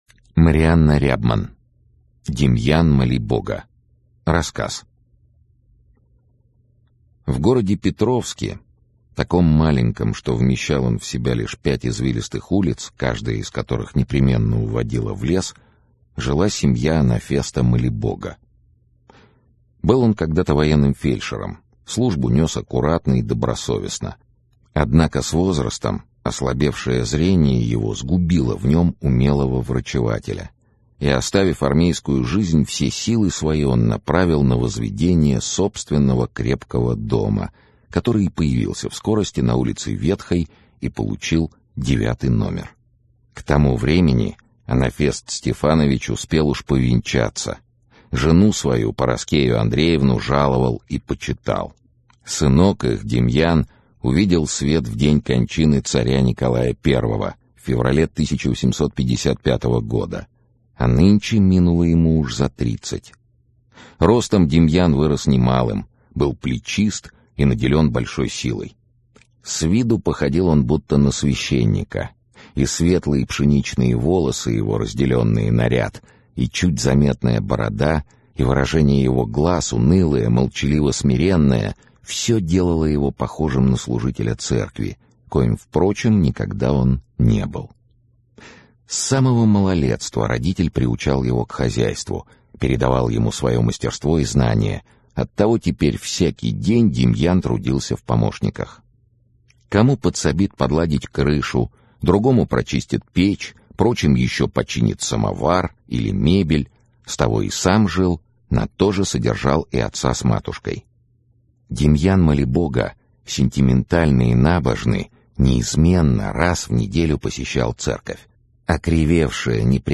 Аудиокнига Прощение | Библиотека аудиокниг
Aудиокнига Прощение Автор Марианна Рябман Читает аудиокнигу Сергей Чонишвили.